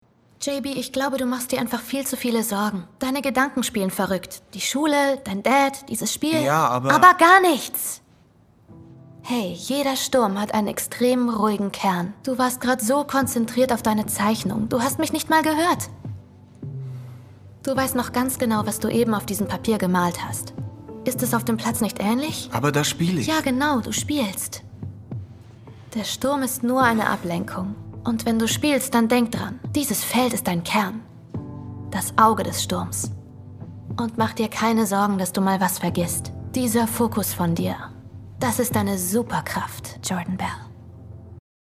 Fränkisch
Synchron Szene